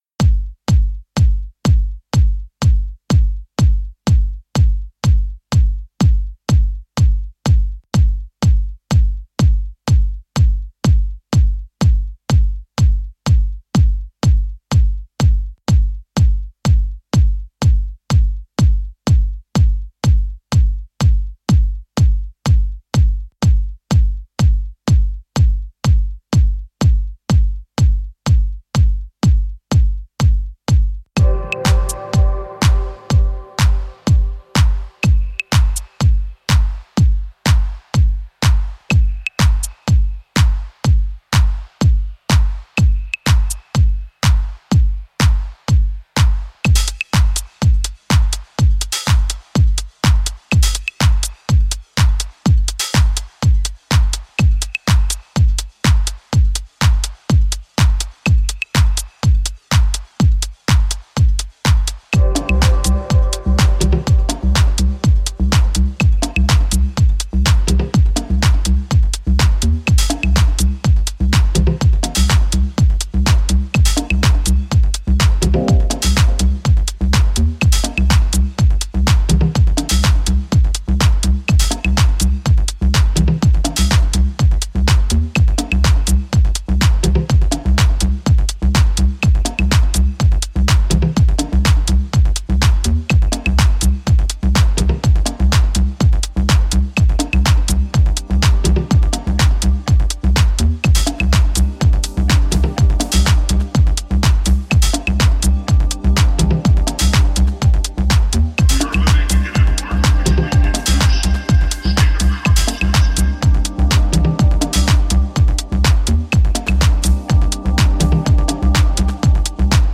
これが中々に素晴らしい内容で、アナログな質感のグルーヴや浮遊コードを駆使したディープ・ハウス群を展開しています。